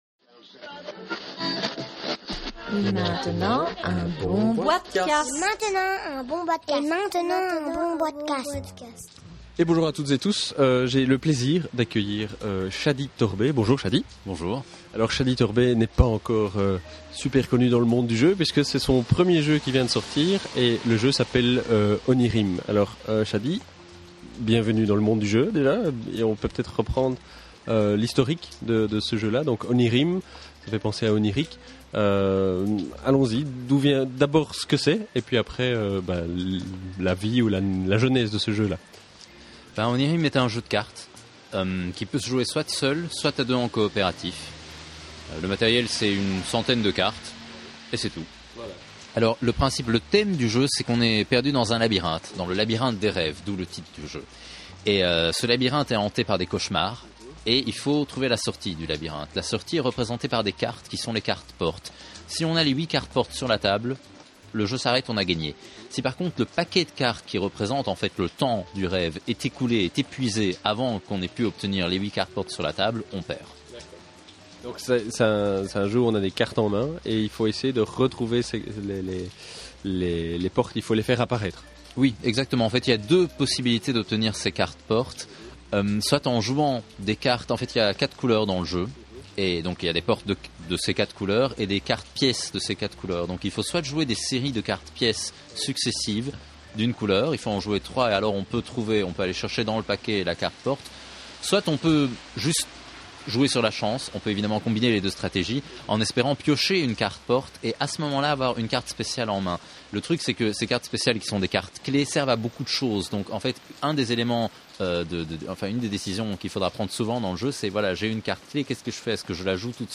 enregistré le 8 septembre 2010 lors d’une Soirée Terrible